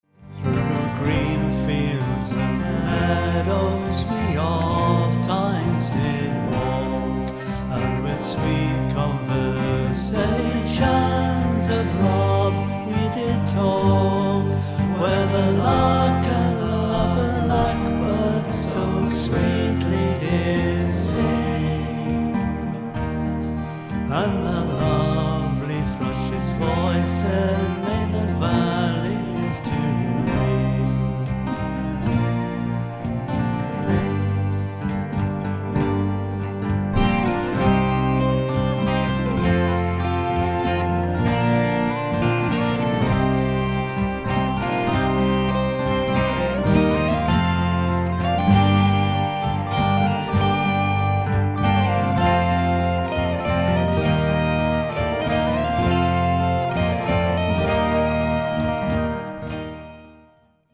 A traditional song